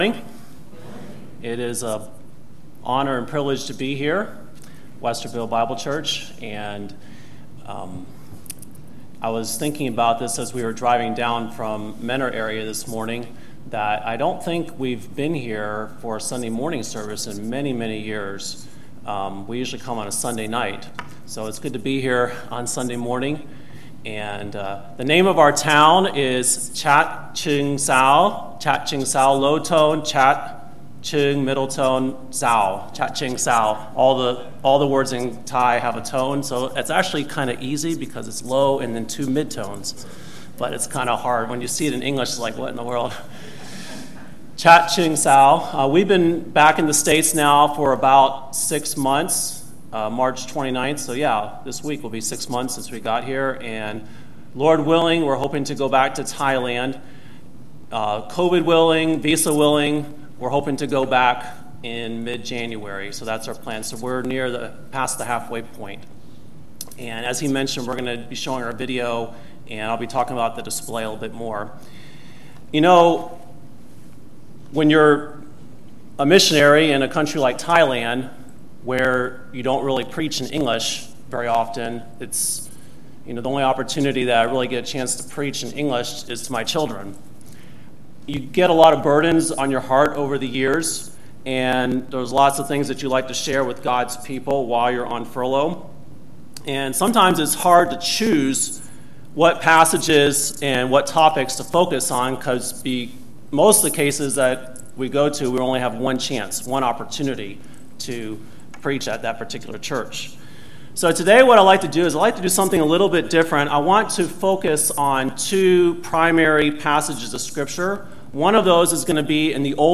ServiceMissionary ConferenceSunday Morning